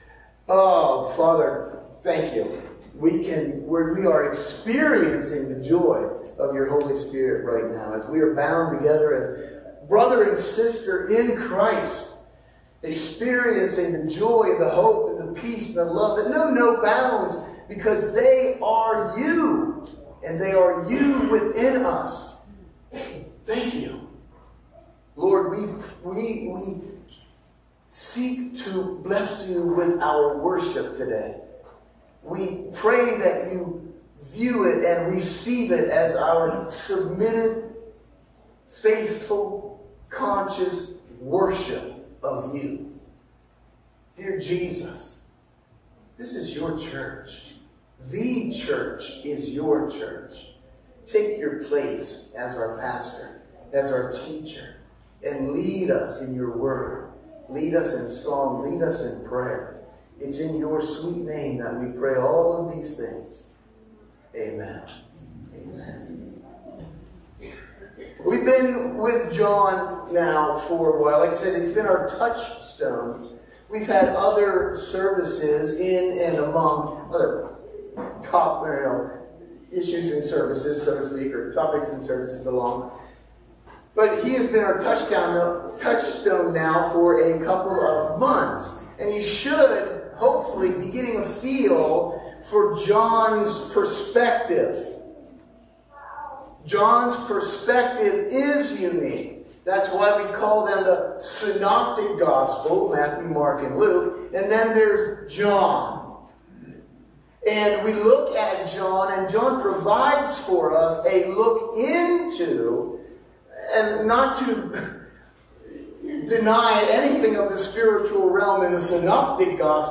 Sunday Morning Service – June 16, 2023